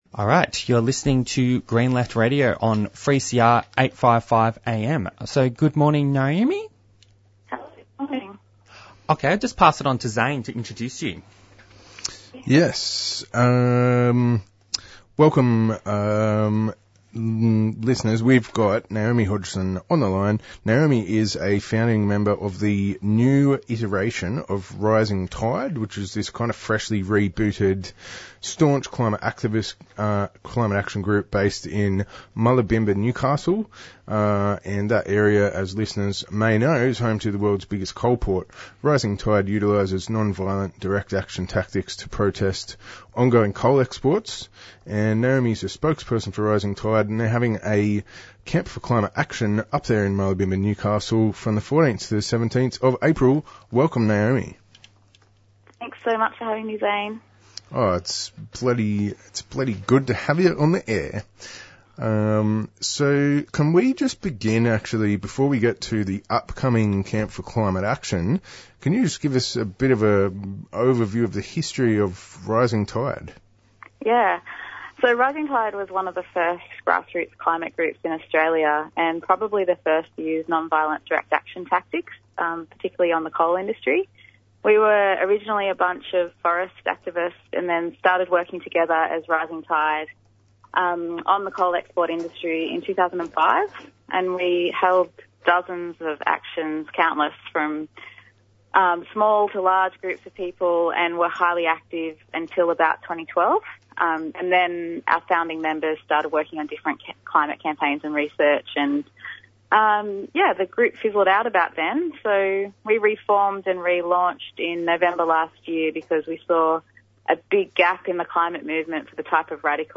Featuring interviews and discussions on why Jim Chalmer's vision of a values-based capitalism is not a real solution to the problems faced by working people and the environment. The upcoming Climate Camp being organised by Rising Tide Newcastle, and the formation of a Independent and Peaceful Australia Network branch in Geelong & South West Victoria.